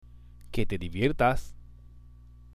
（ケテ　ディビエルタス！）